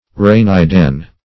Search Result for " araneidan" : Wordnet 3.0 ADJECTIVE (1) 1. relating to or resembling a spider ; [syn: araneidal , araneidan ] The Collaborative International Dictionary of English v.0.48: Araneidan \Ar`a*ne"i*dan\, a. (Zool.)